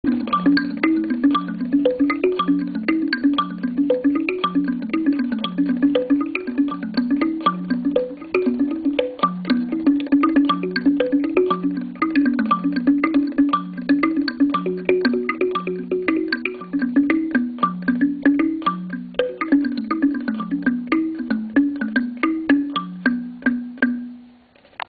Marimba
Mapa-5-–-Audio-Marimba.mp3